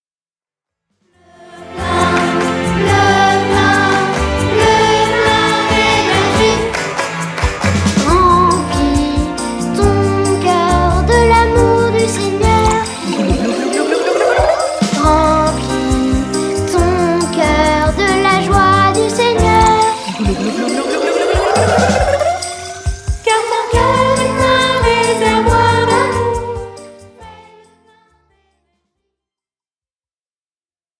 10 chants rythmés et enjoués